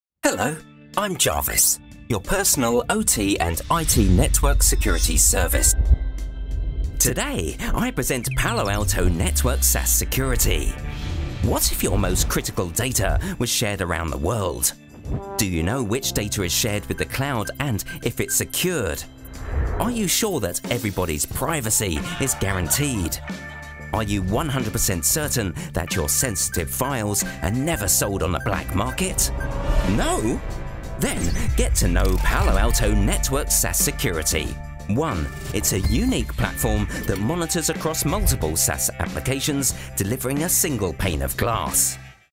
Anglais (britannique)
Vidéos explicatives
Apprentissage en ligne
Vidéos d'entreprise
Neumann TLM-103
Cabine insonorisée
Âge moyen
Baryton